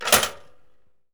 household
Toaster Noise